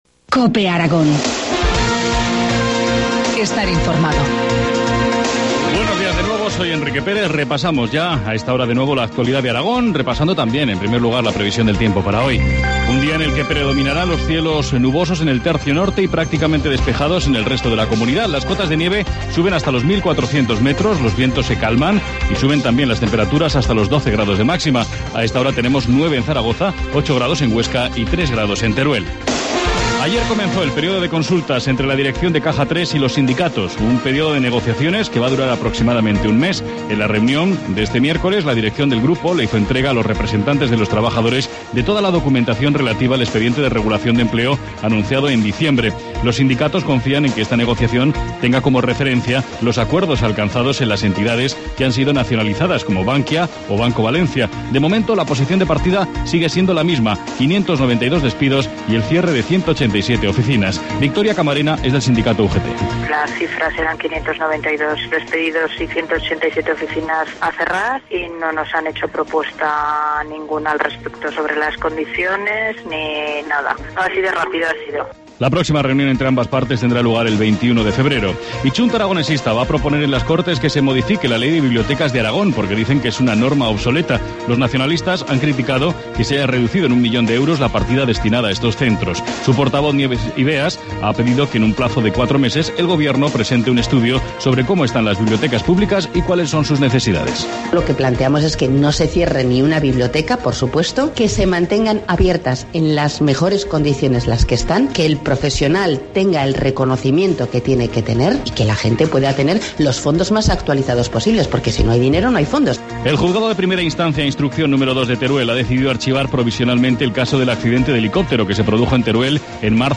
Informativo matinal, jueves 14 de febrero, 8.25 horas